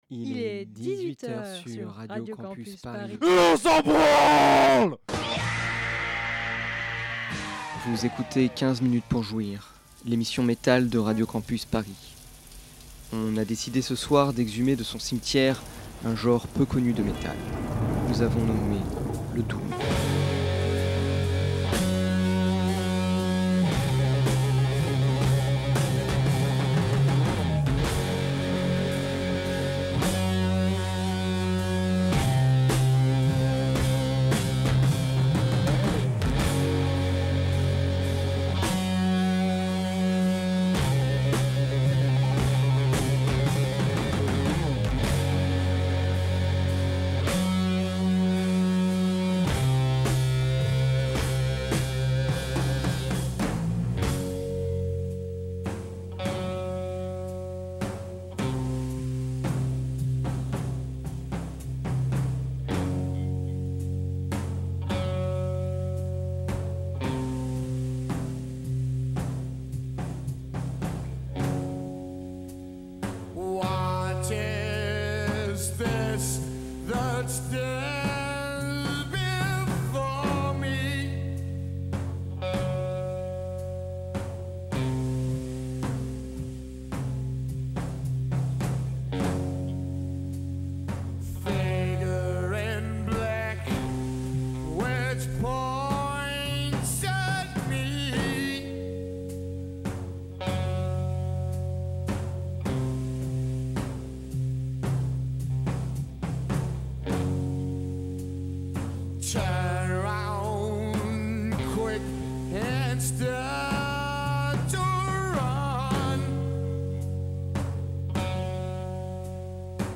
Prenant le contre-pied des obsédés de la double pédale et des riffs effrénés, le Doom ralentit le rythme pour faire durer le plaisir très... très longuement. Le son reste lourd, on ne baise pas gentiment les tympans mais on les alanguit à coup de thématiques morbides voir funèbres.